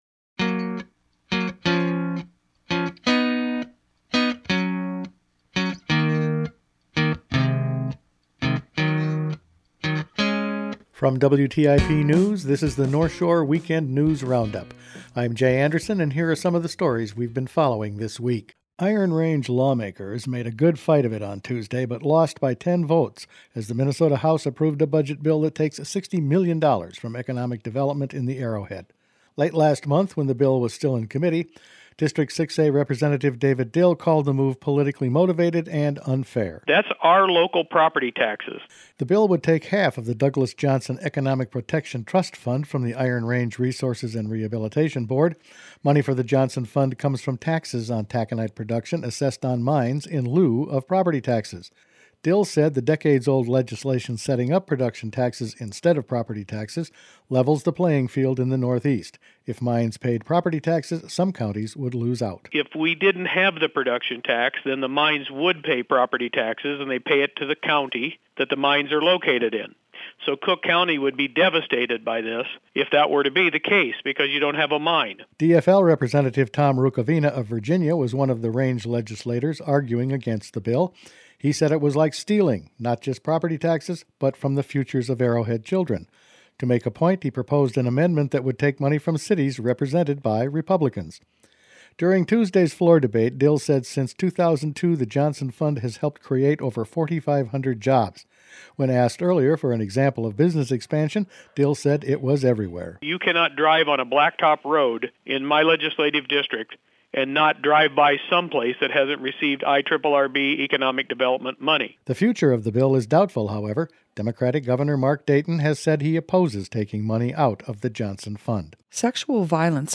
Weekend News Roundup for April 9
Each weekend WTIP news produces a round up of the news stories they’ve been following this week. Angry Iron Range Legislators, sexual violence awareness and a brief tax primer were all in this week’s news.